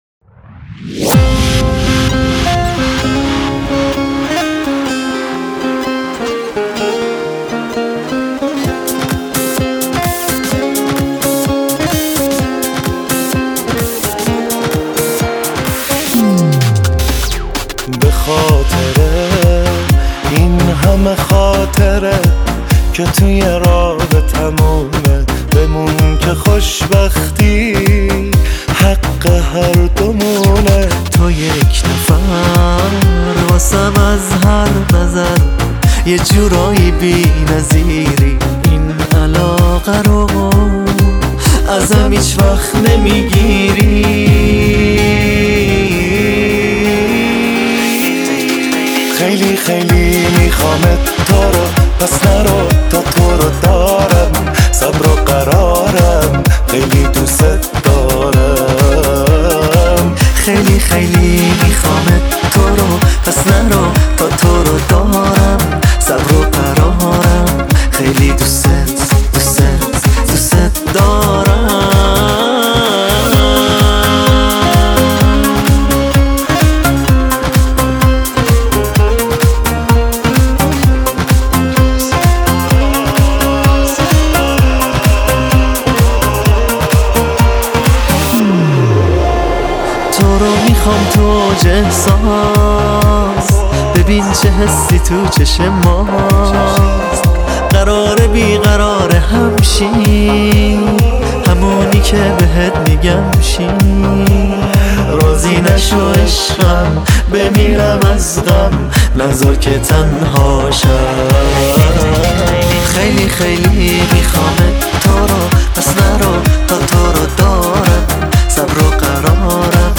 تک آهنگ جدید
دو صدایی